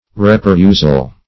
Reperusal \Re`pe*rus"al\ (r?`p?-r?z"al), n.